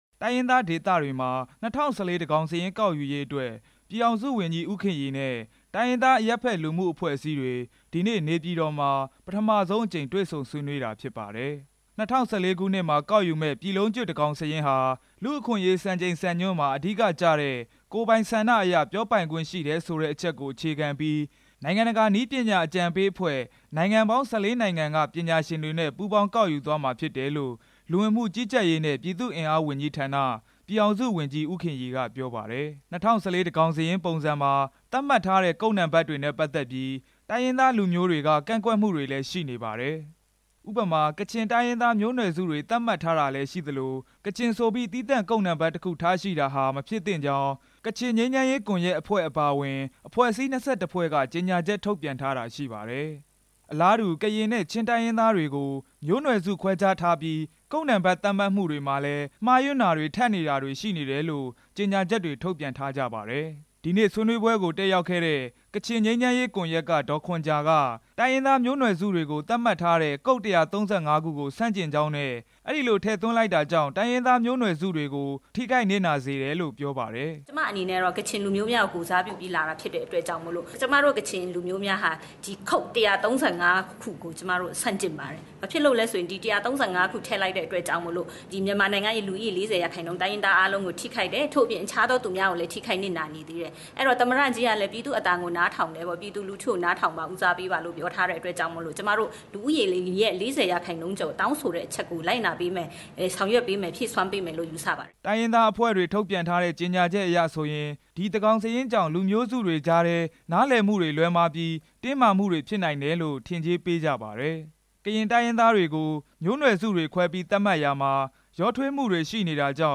ဒီနေ့ နေပြည်တော် Mount Pleasant Hotel မှာ ကျင်းပတဲ့ ၂၀၁၄ သန်းခေါင်စာရင်း ကောက်ခံမှုနဲ့ ပတ်သက်ပြီး တိုင်းရင်းသား အရပ်ဘက် လူမှုအဖွဲ့ အစည်းတွေက ကိုယ်စားလှယ်တွေနဲ့ တွေ့ဆုံရာမှာ ဝန်ကြီး ဦးခင်ရီ ပြောလိုက်တာပါ။